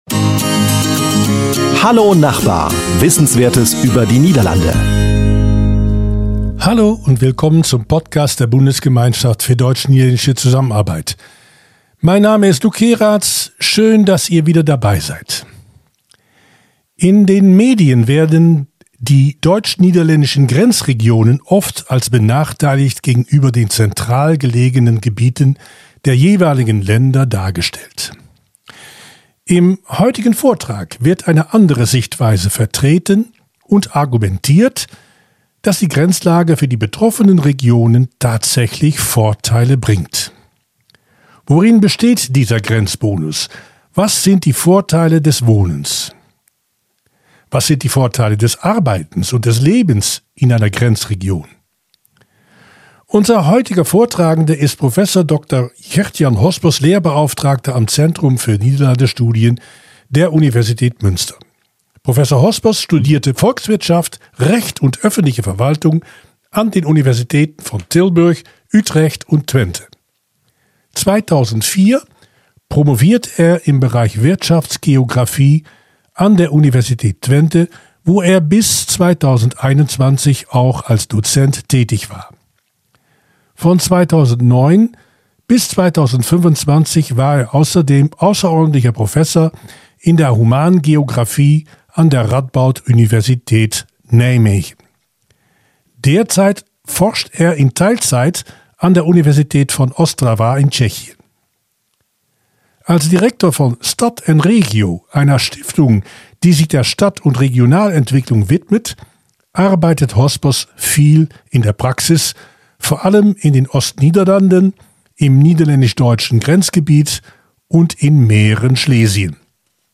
Im heutigen Vortrag wird eine andere Sichtweise vertreten und argumentiert, dass die Grenzlage für die betroffenen Regionen tatsächlich Vorteile bringt. Worin besteht dieser Grenzbonus? Was sind die Vorteile des Wohnens, Arbeitens und Lebens in einer Grenzregion?